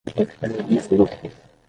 Произносится как (IPA)
/veʁˈmi.fu.ɡu/